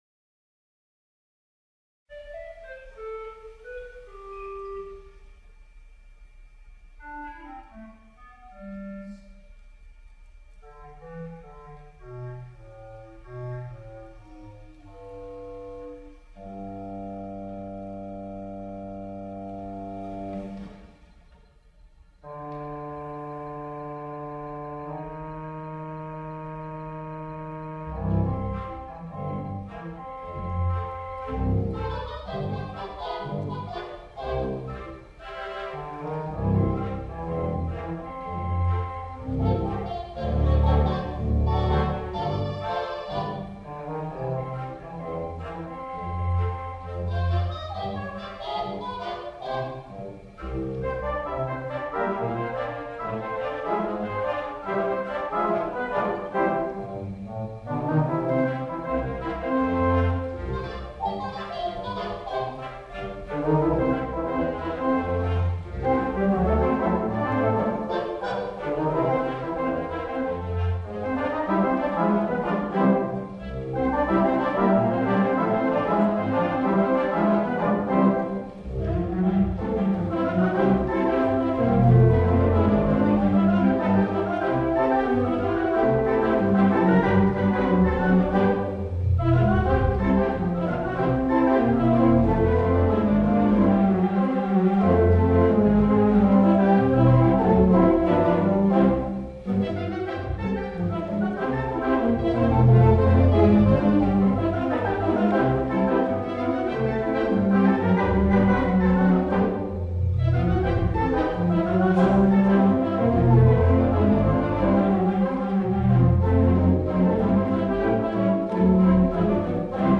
3/16 Mighty WurliTzer Theatre Pipe Organ
The recording was made using his Samson Zoom H4 digital audio recorder like the one we use at the Walnut Hill Office of Operations.